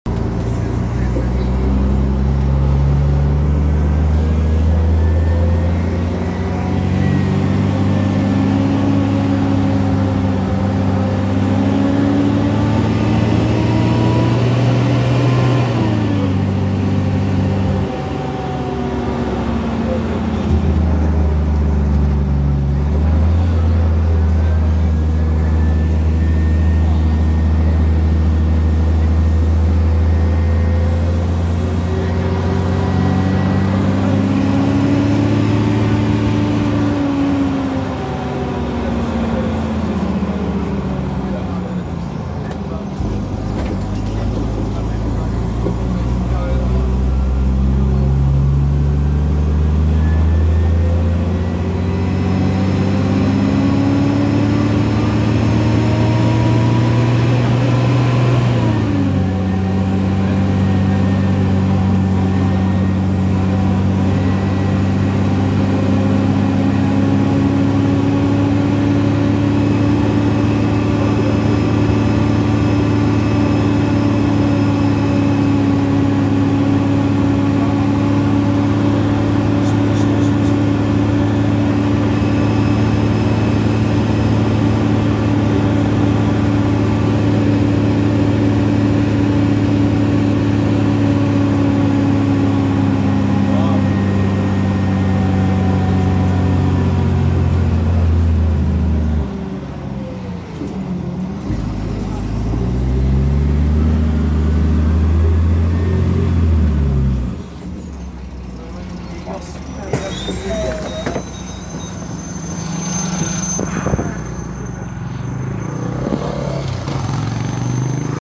Hocam bu sabah taze cngli karsan avancity sesi kaydettim motorun dibinden youtube'a yüklerim bi ara Genişletmek için tıkla ... MÜBAREK M60 TANKI GİBİ Ekli dosyalar 1002 (3).MP3 1002 (3).MP3 1.8 MB